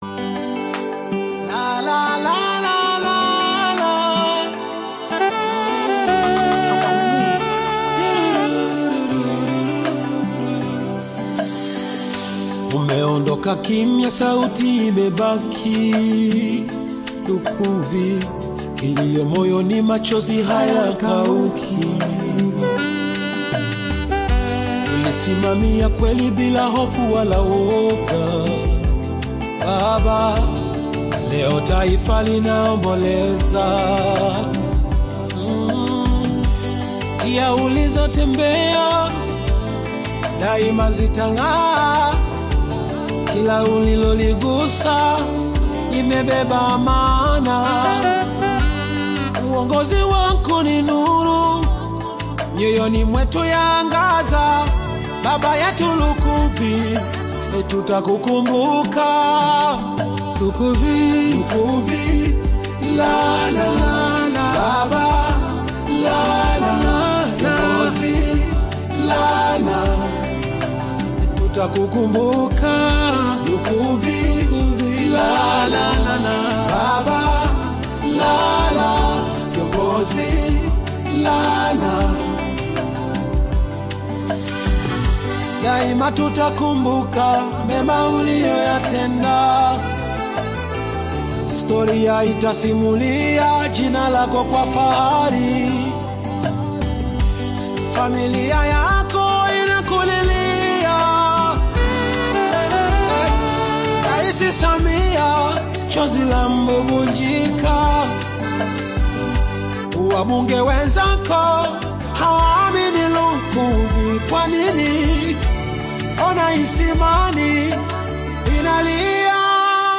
gospel
touching tribute song